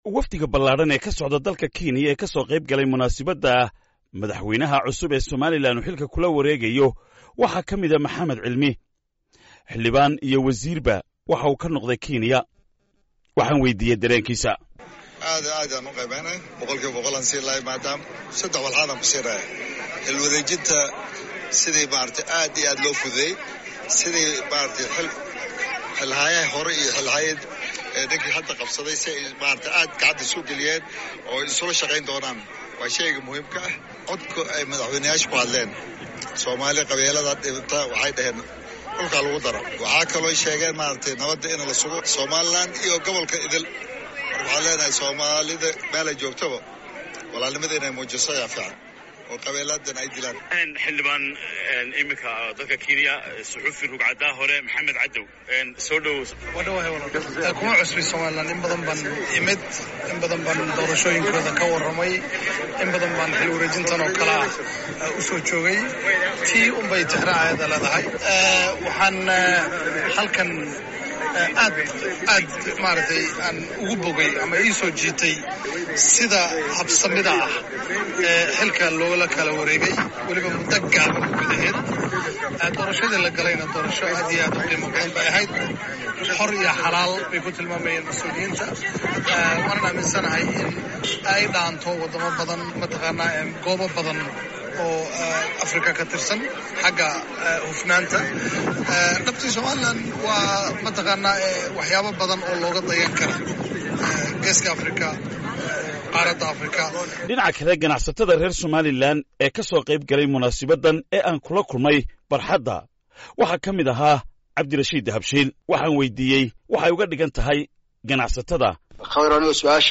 Qaar ka mid ah dadkii ka qeyb-galay xafladda